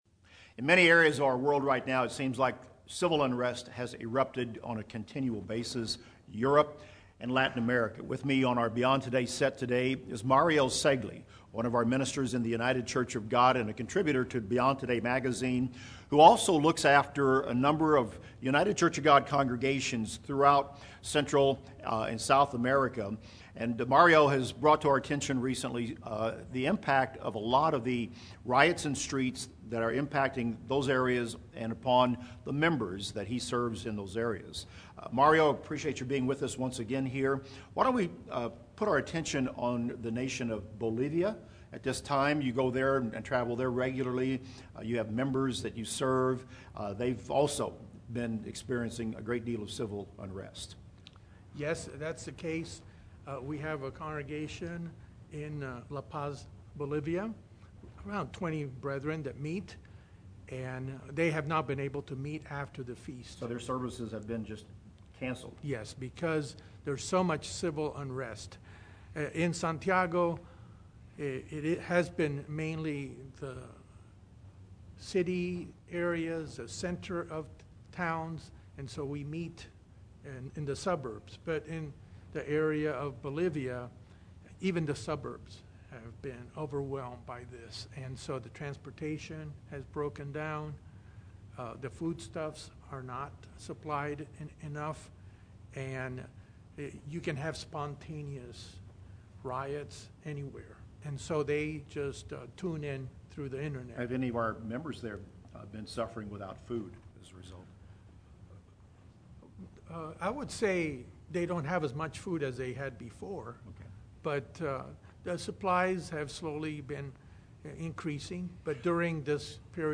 talks with special guest